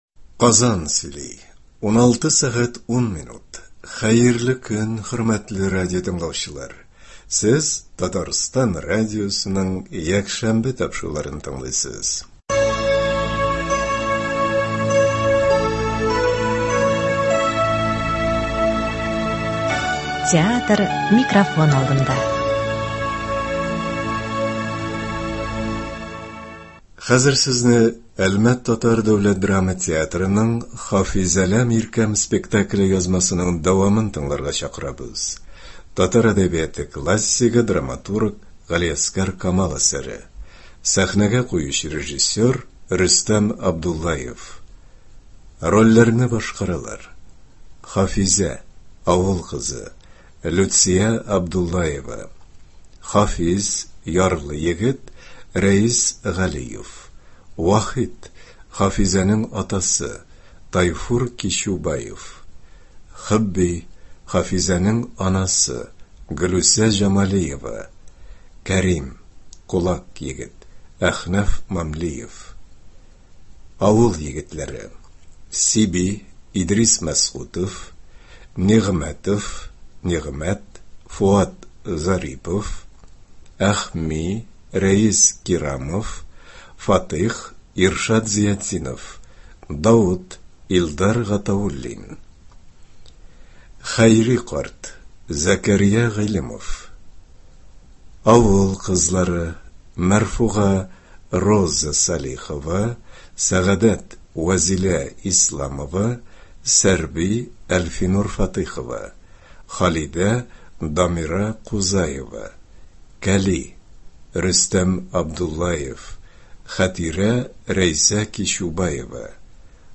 Әлмәт ТДДТ спектакленең радиоварианты.